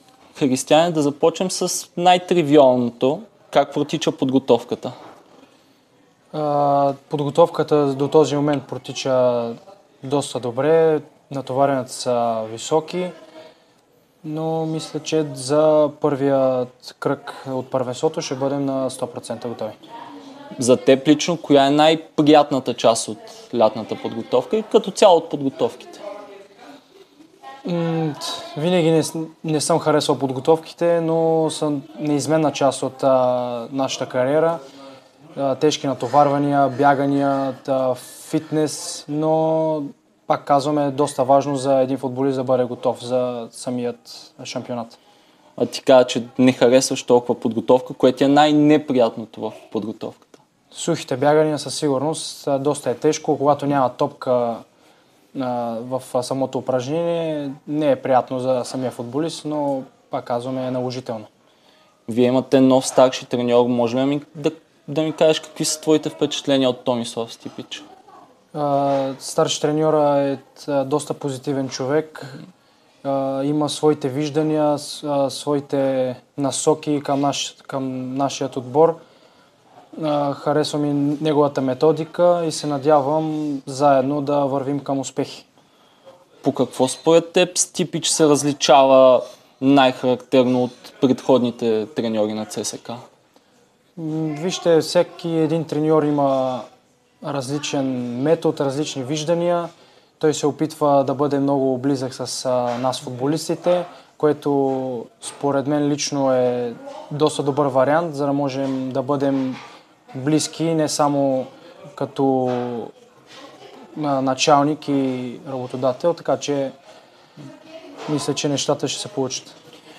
22-годишният защитник даде ексклузивно интервю пред Дарик радио и dsport, в което коментира различни теми, свързани с „армейците“ и с него самия.